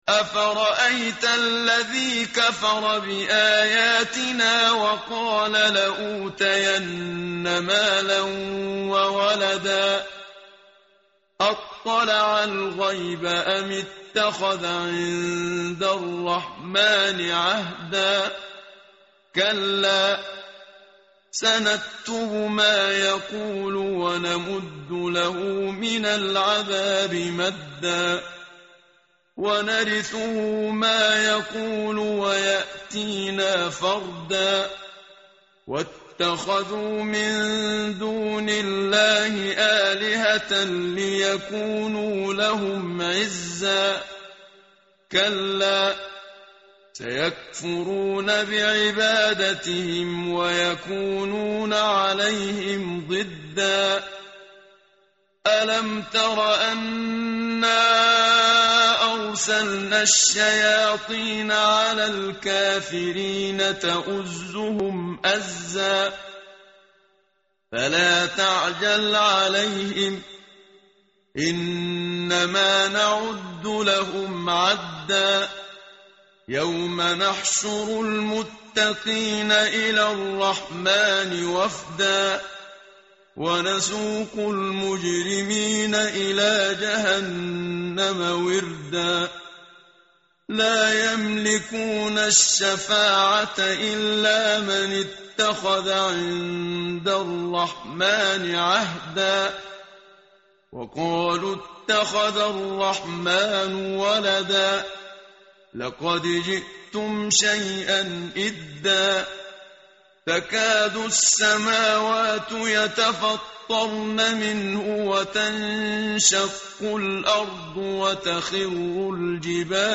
tartil_menshavi_page_311.mp3